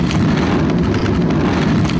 scrape1.ogg